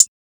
kits/OZ/Closed Hats/hihat (LosAngelos).wav at main
hihat (LosAngelos).wav